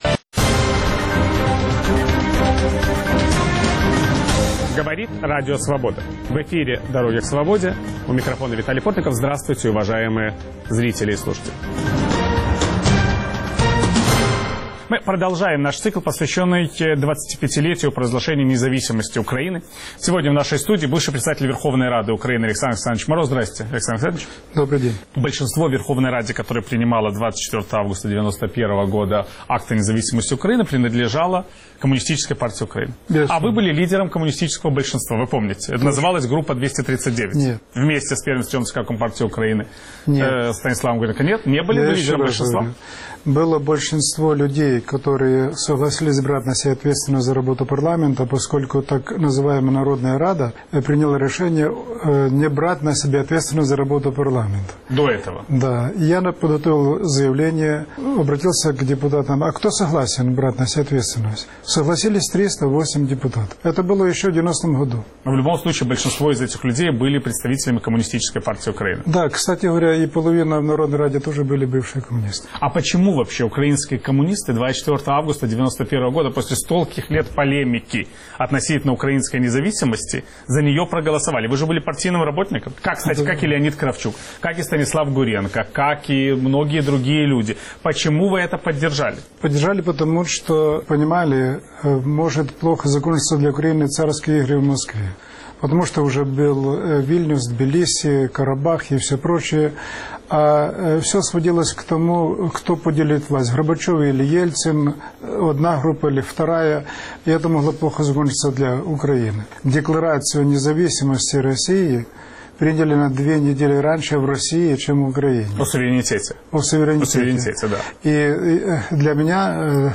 Мы продолжаем цикл программ, посвящённых 25-летию провозглашения независимости Украины. Виталий Портников беседует с бывшим председателем Верховной Рады Украины Александром Морозом.